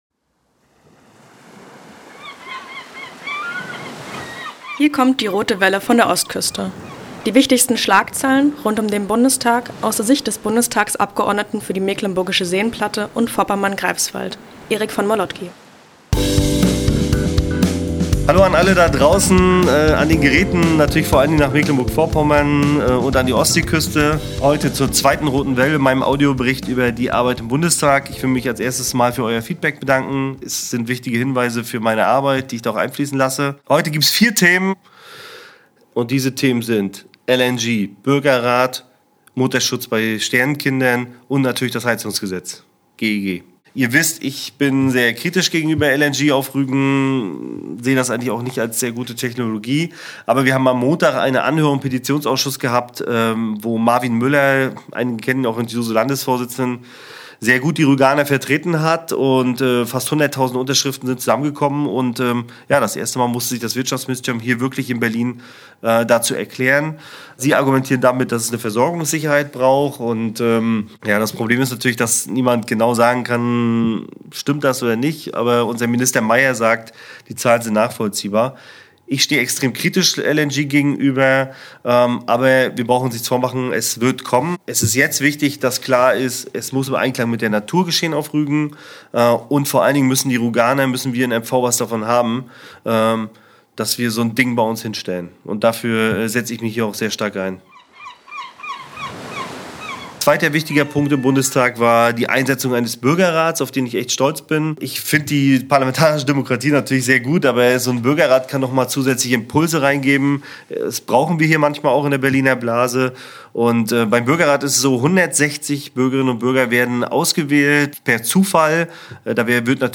Direkt aus dem Bundestag und mit einem besonderen Gruß an die Ostseeküste und nach Mecklenburg-Vorpommern, berichtet Erik von den Themen, die ihm am Herzen liegen. Erstens, das heiße Eisen LNG auf Rügen.